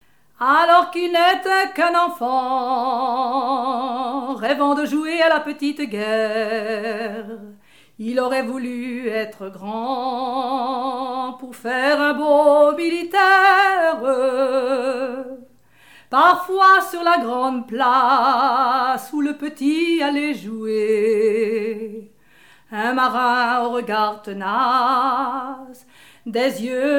Genre strophique
la danse la ridée et chansons
Pièce musicale inédite